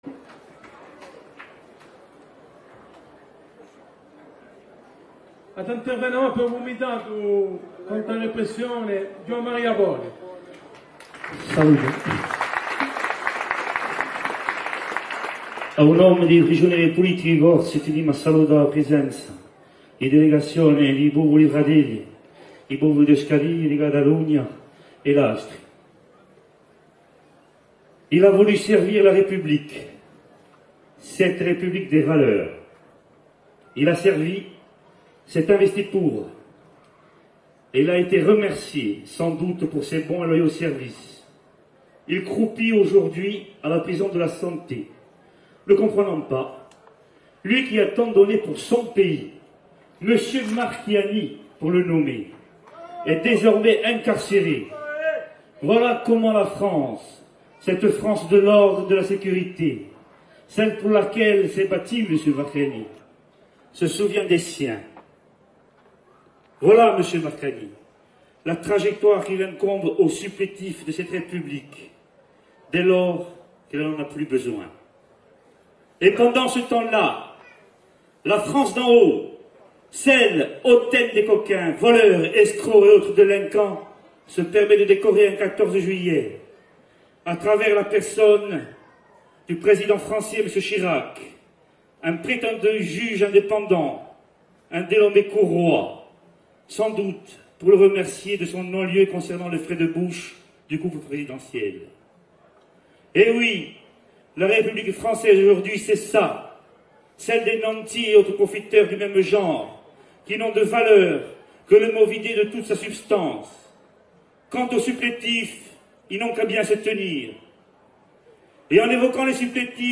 DISCOURS DE CLOTURE CAR